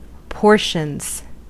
Ääntäminen
Ääntäminen US Haettu sana löytyi näillä lähdekielillä: englanti Käännöksiä ei löytynyt valitulle kohdekielelle. Portions on sanan portion monikko.